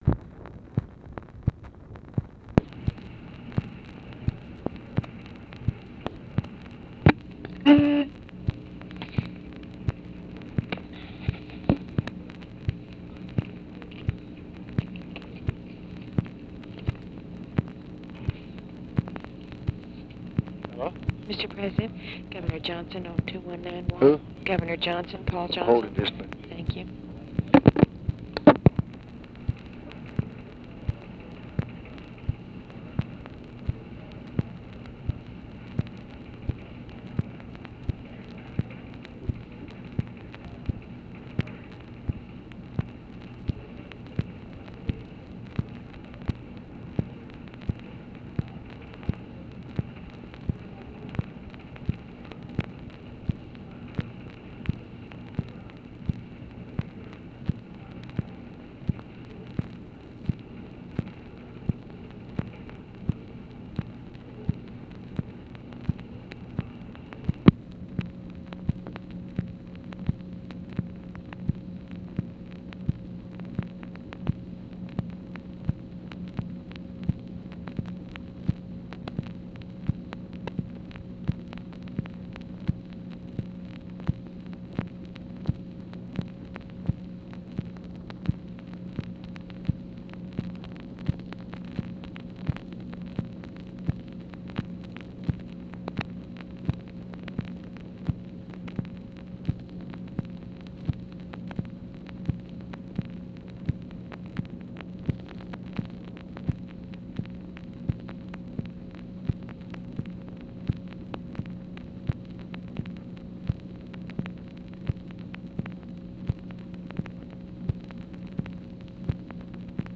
Conversation with PAUL JOHNSON and OFFICE SECRETARY, August 6, 1965
Secret White House Tapes